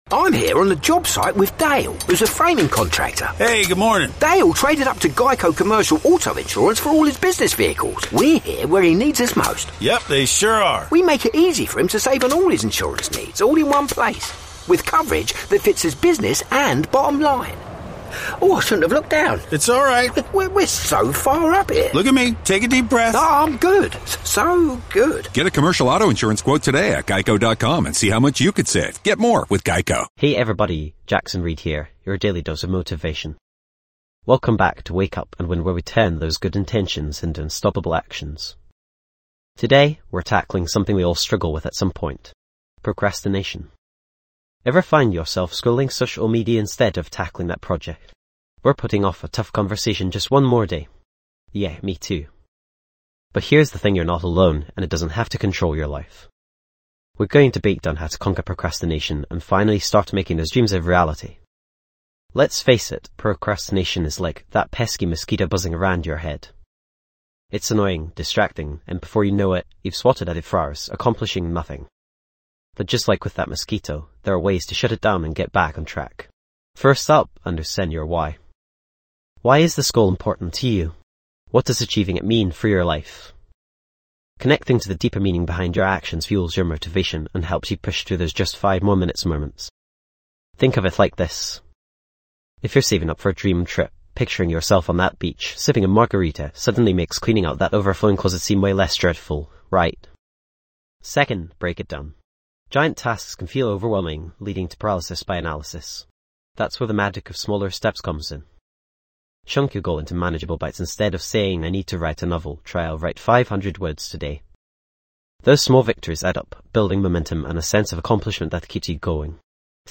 Podcast Category:. Personal Development Motivational Speeches
This podcast is created with the help of advanced AI to deliver thoughtful affirmations and positive messages just for you.